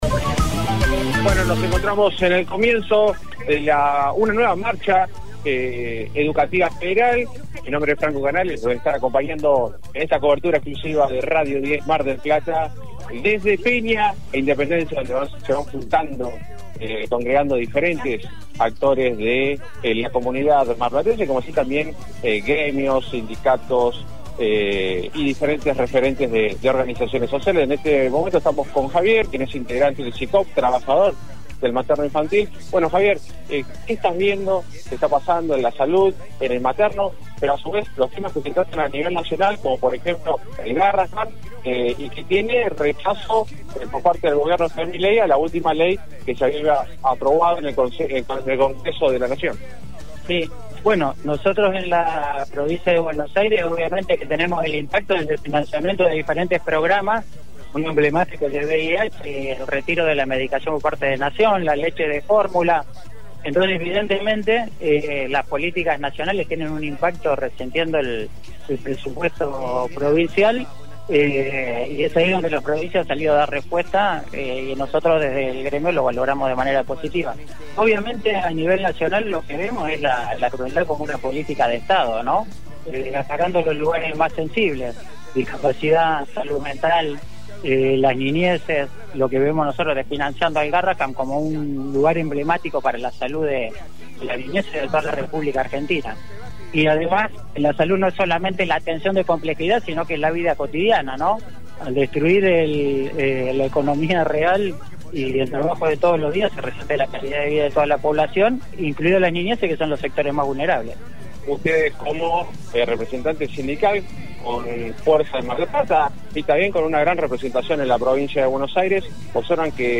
Radio 10 Mar del Plata transmitió en vivo la movilización que recorrió el centro de la ciudad con sindicatos, estudiantes, jubilados y organizaciones sociales.
Voces de la calle